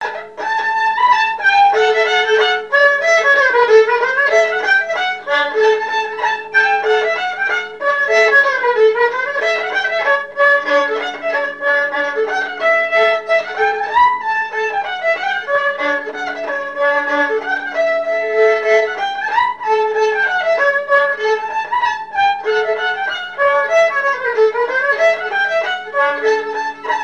Saint-Michel-Mont-Mercure
danse : scottich trois pas
Pièce musicale inédite